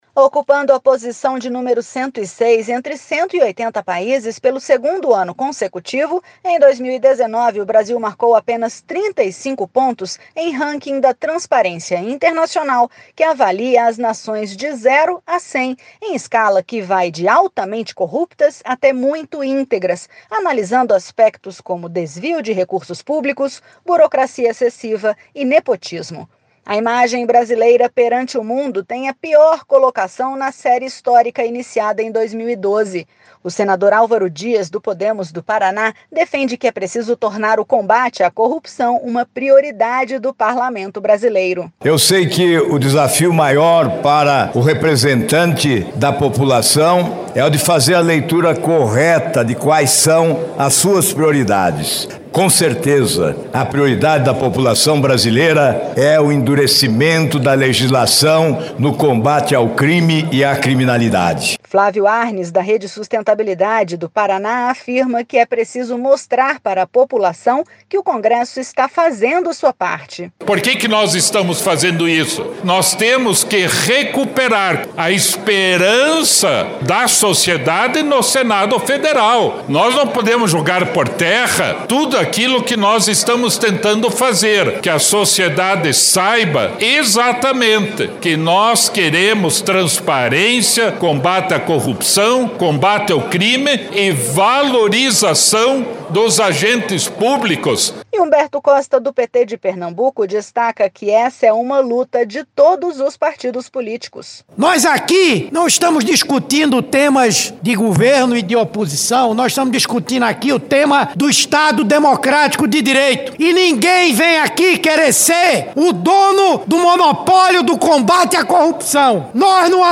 O senador Álvaro Dias, do Podemos do Paraná, defende que é preciso tornar o combate à corrupção uma prioridade do parlamento brasileiro.